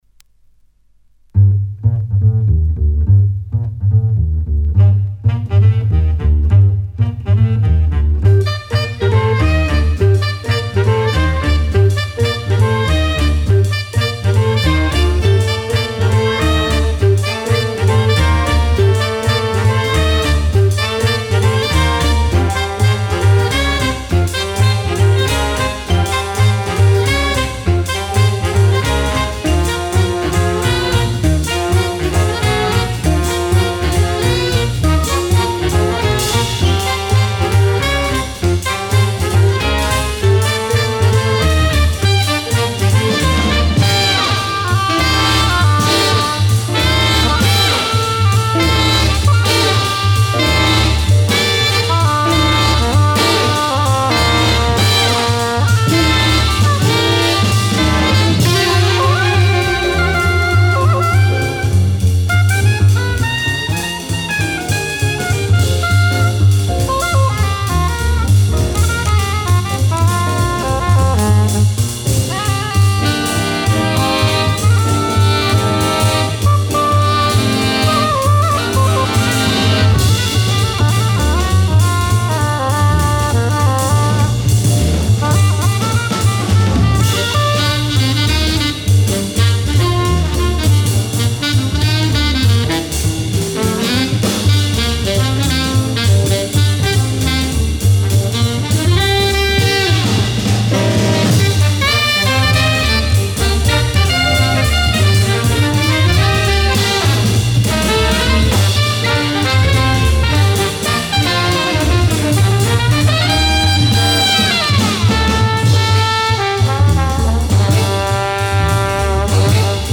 dynamische, stuiterende baspartijen